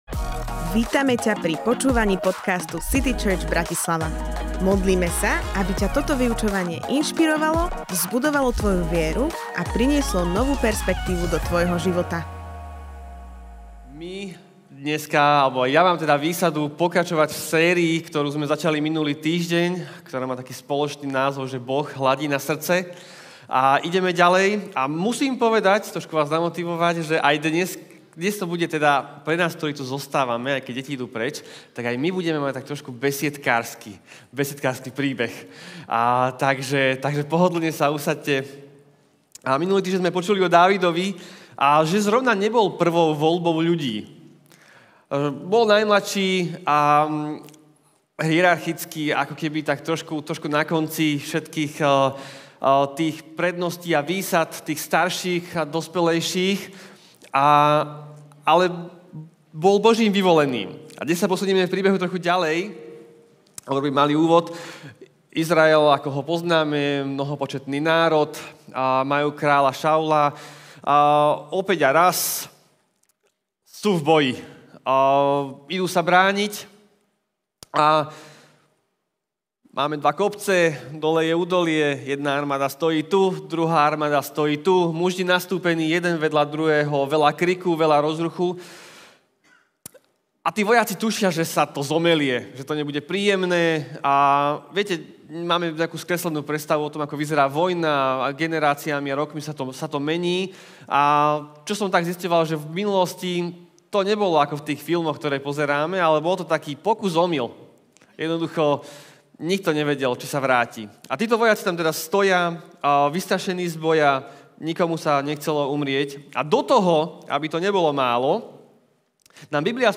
Kázeň týždňa